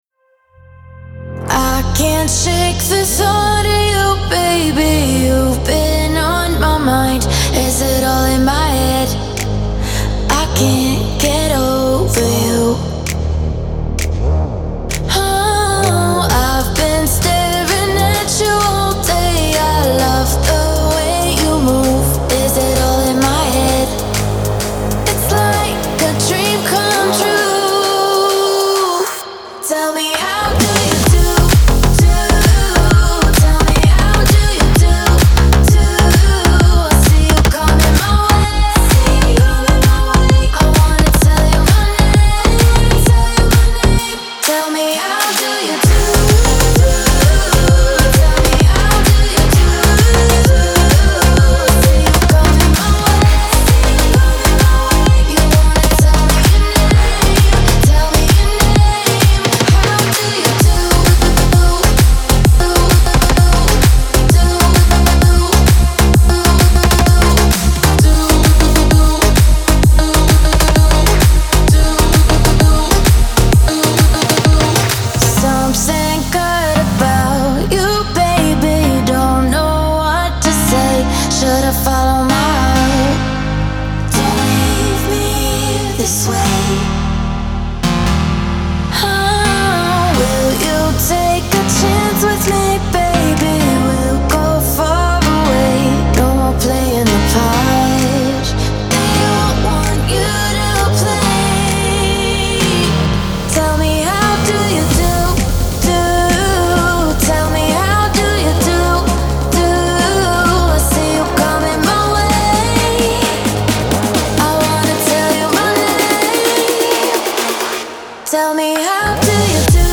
Genre: Progressive House, Progressive Trance, Electronic.